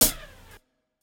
kits